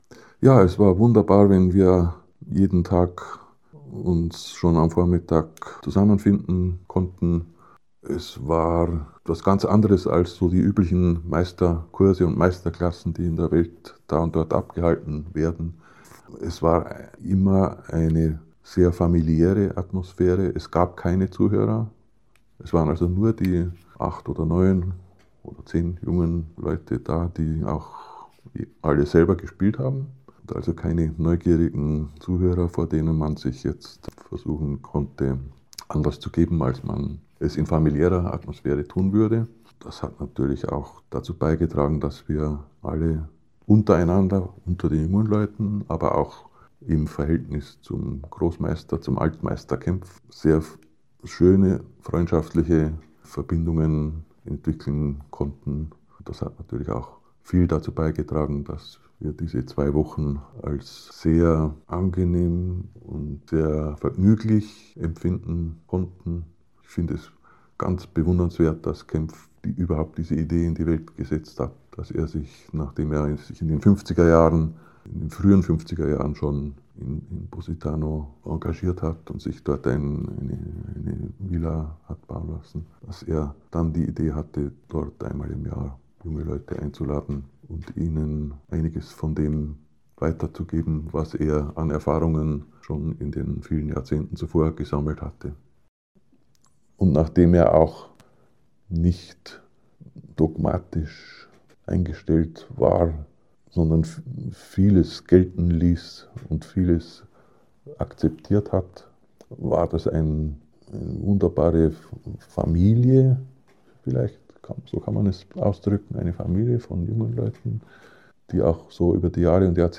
In eight chapters, he reports on the masterclasses at Casa Orfeo and his encounters with Wilhelm Kempff.